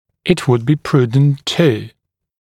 [ɪt wud biː ‘pruːdnt tuː][ит ууд би: ‘пру:днт ту:]было бы благоразумно …